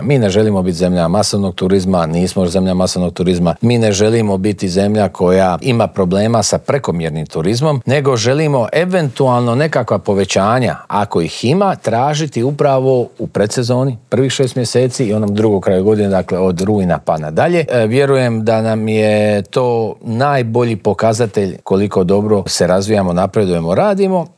O dosad ostvarenim rezultatima, očekivanjima od ljetne turističke sezone, ali i o cijenama smještaja te ugostiteljskih usluga razgovarali smo u Intervjuu tjedna Media servisa s ministriom turizma i sporta Tončijem Glavinom.